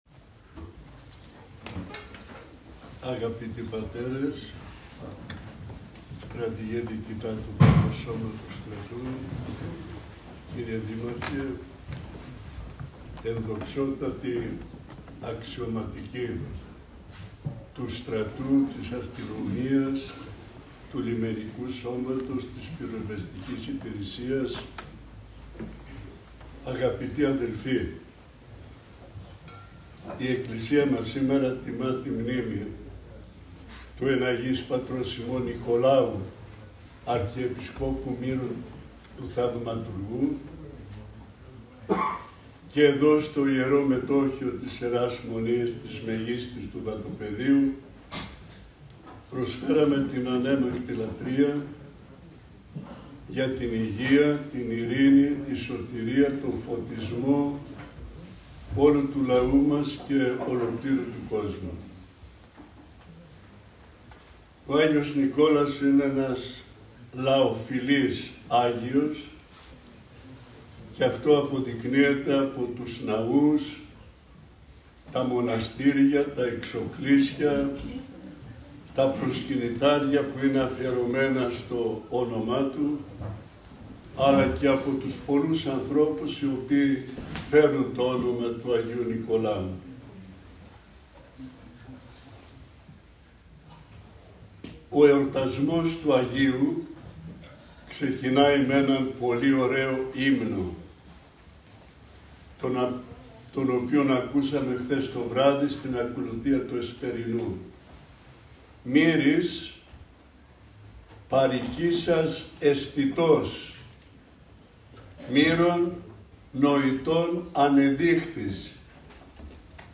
Την πανηγυρική Αρχιερατική Θεία Λειτουργία τέλεσε ο Μητροπολίτης Ξάνθης και Περιθεωρίου κ. Παντελεήμων ο οποίος και κήρυξε το θείο λόγο.
Ακούστε το κήρυγμα του Σεβασμιωτάτου: